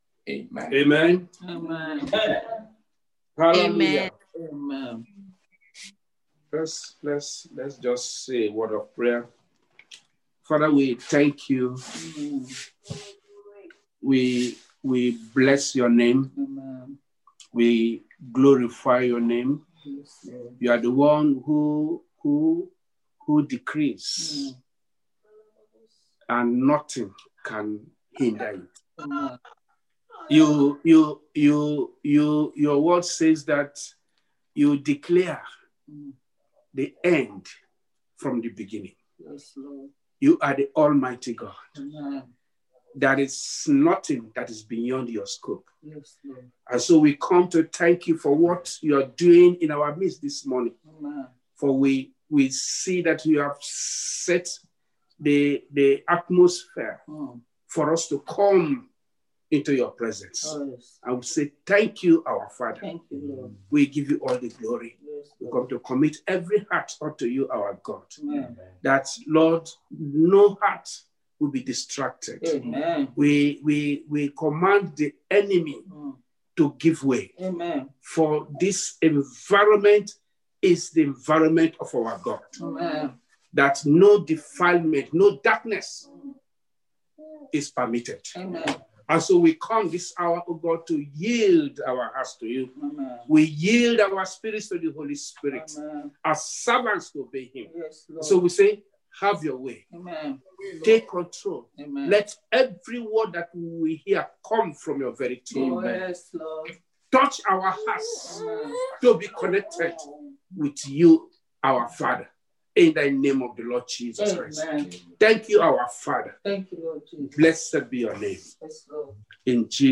Exhortation- Be ye reconciled to God